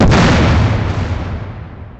blast.wav